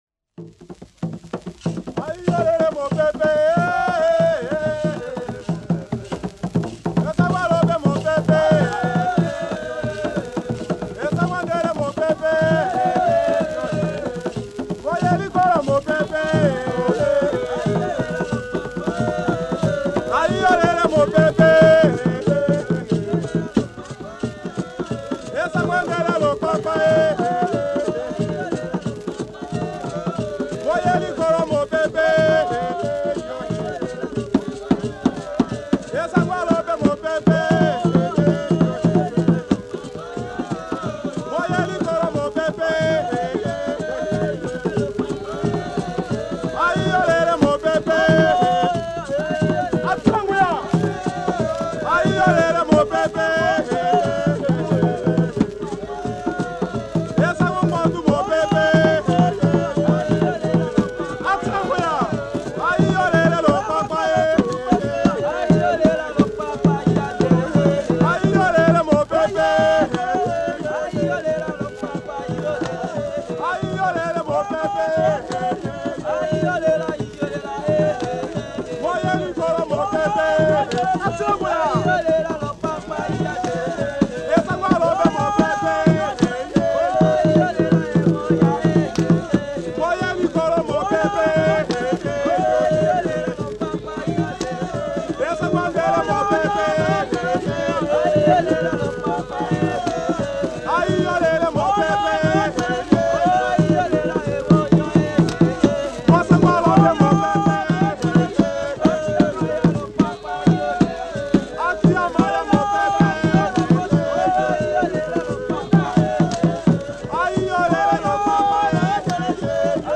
Buja soilders of the Force Publique
Folk music
Field recordings
sound recording-musical
Recorded at the camp Militaire, at Gombari, on the northern edge of the great Ituri Forest in north eastern Congo.
Topical song with 2 bass drums.